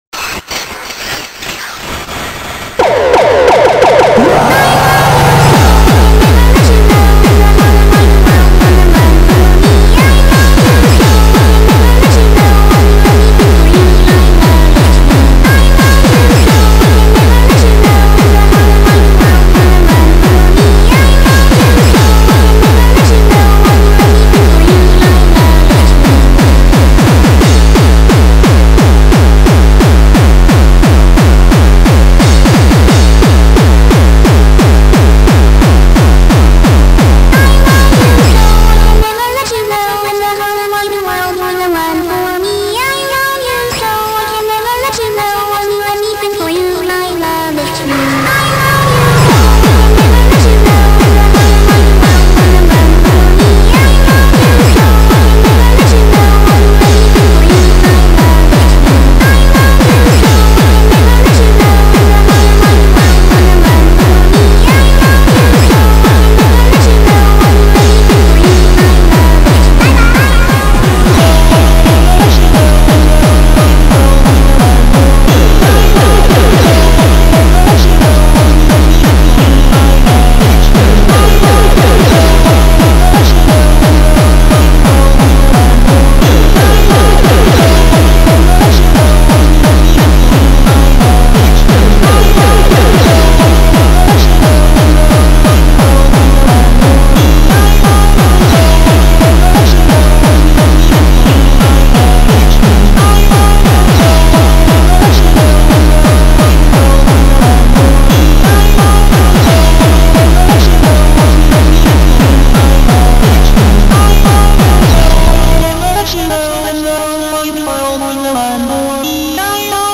با ریتمی تند و سریع مخصوص ادیت
فانک